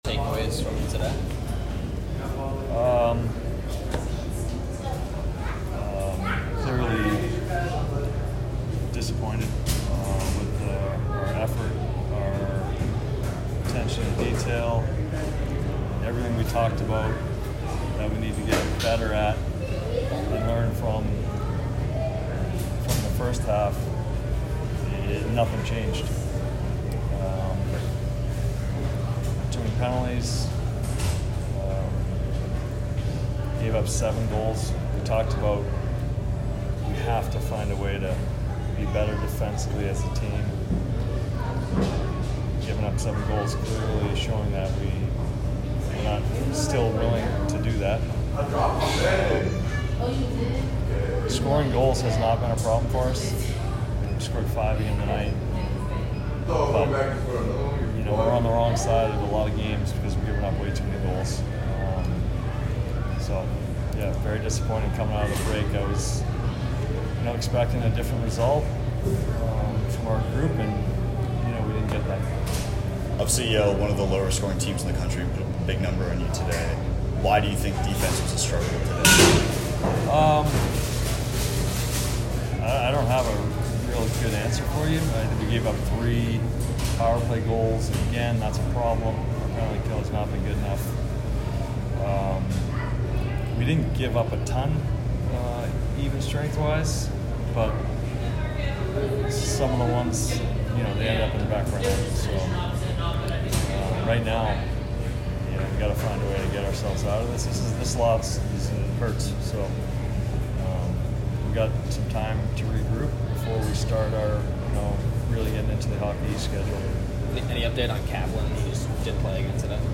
Boston University Athletics